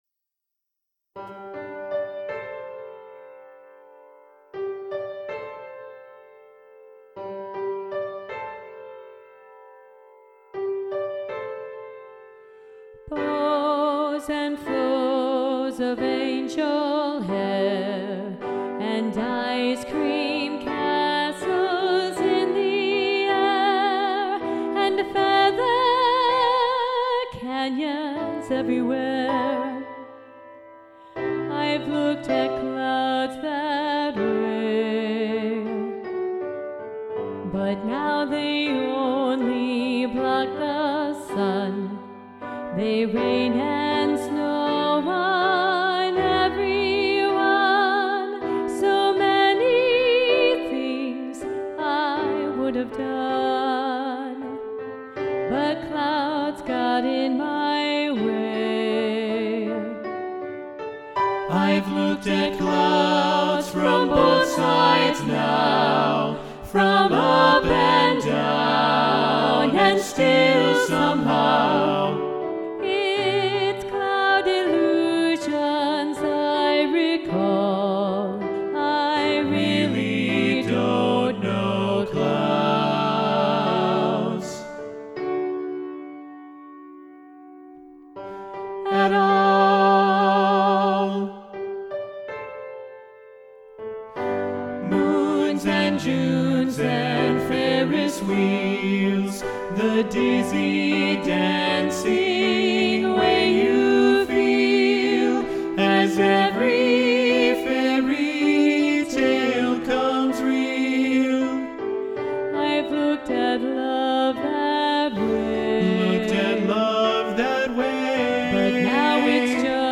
Soprano 2 Muted
Both-Sides-Now-SATB-Soprano-2-Muted-arr.-Roger-Emerson.mp3